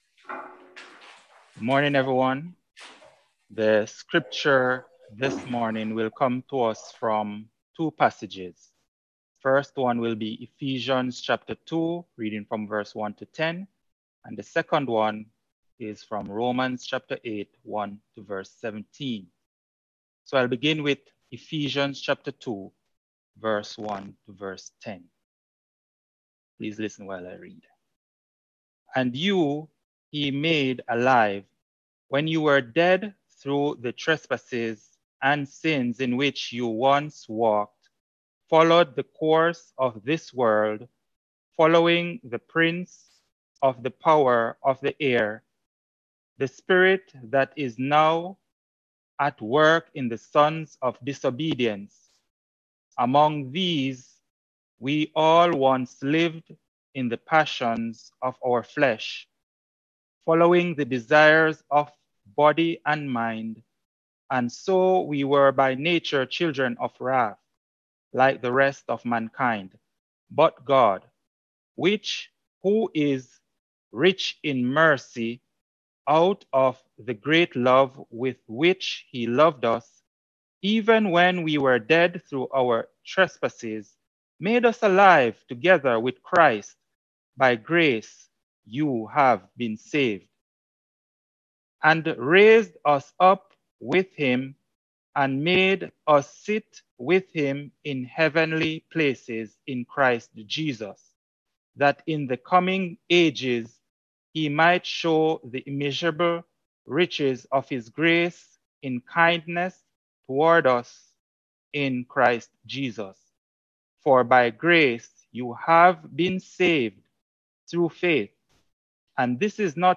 Transforming Grace: The Problem of Unbelief & Sin :Bethesda Sermon Audio